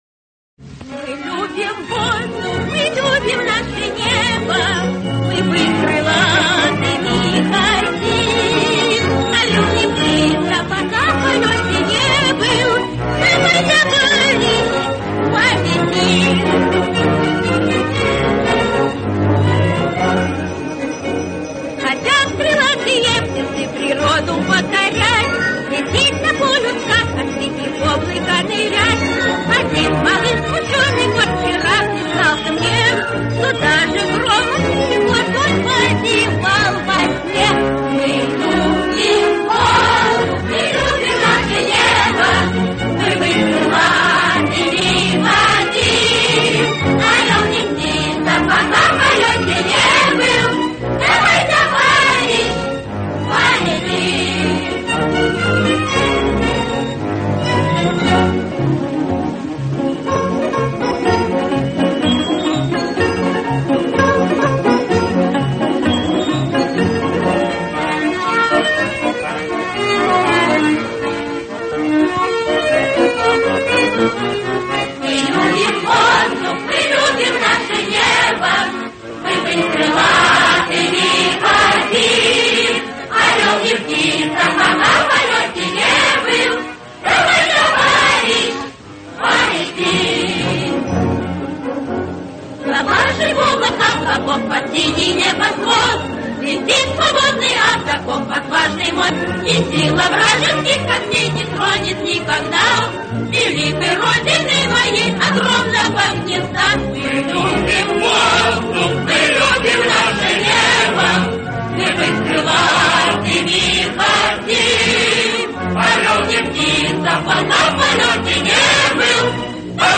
Жизнерадостная песня будущих советских авиаторов.